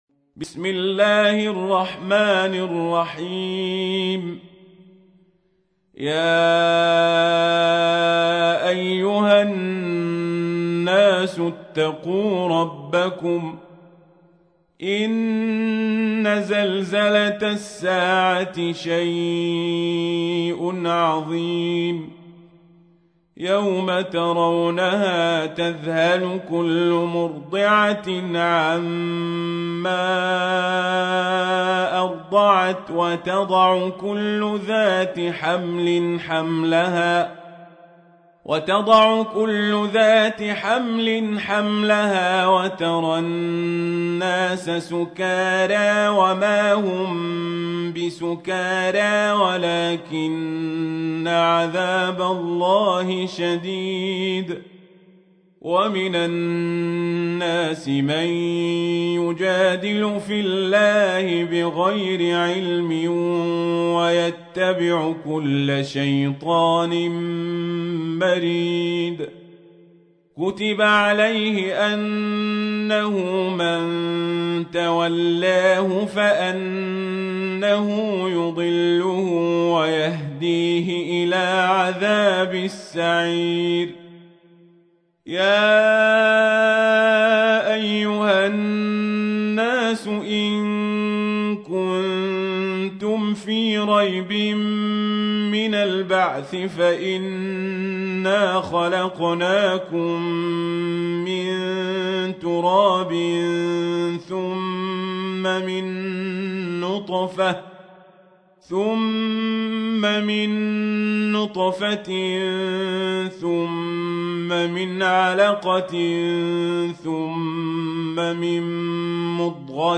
تحميل : 22. سورة الحج / القارئ القزابري / القرآن الكريم / موقع يا حسين